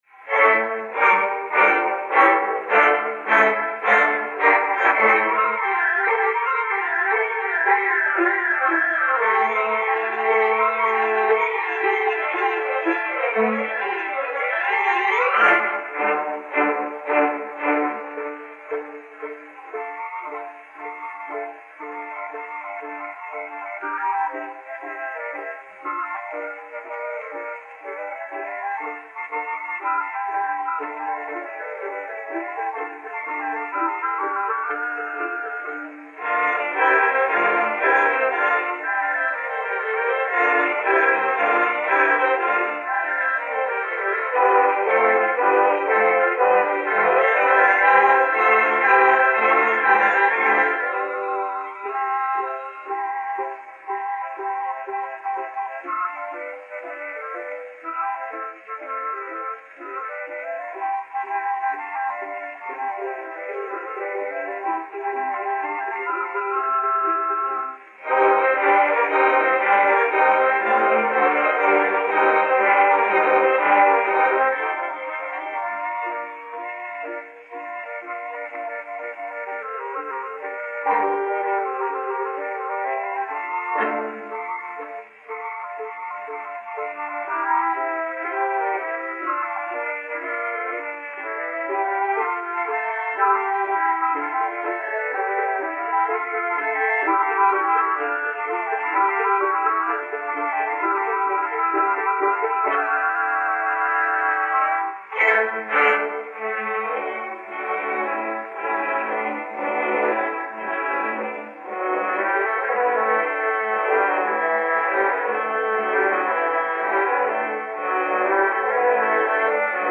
Les danses viennent ensuite, et forment une sorte de triptyque musical : d'abord une danse grecque où les motifs très caractéristiques se développent avec une harmonieuse eurythmie ; puis une expressive et douloureuse scène pantomimique : la Troyenne regrettant sa patrie perdue ; enfin une danse tour à tour emportée et gracieuse, après quoi revient le chœur de glorification.